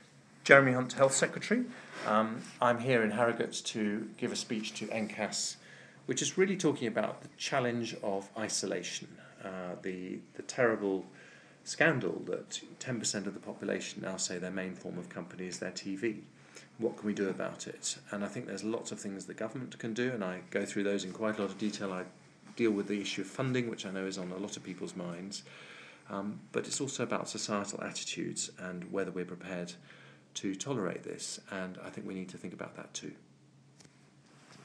Jeremy Hunt, Secretary of State for Health, previews his closing speech at NCAS 2013